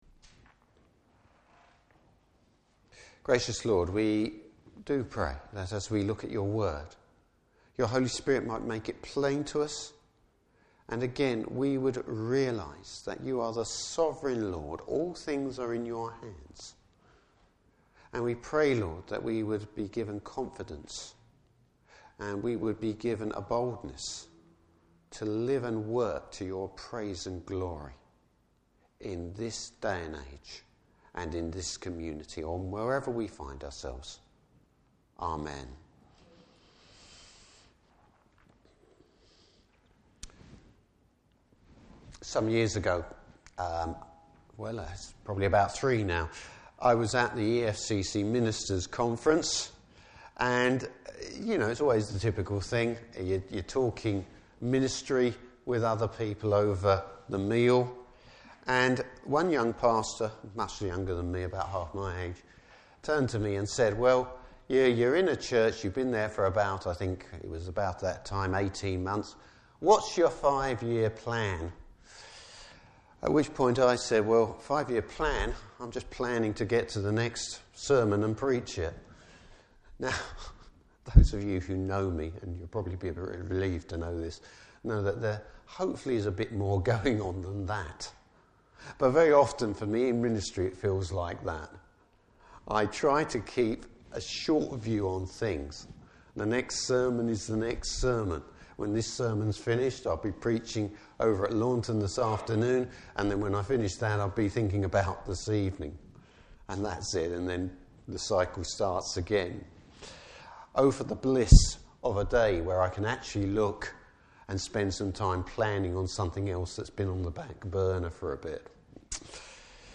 Service Type: Morning Service Bible Text: Matthew 2:13-23.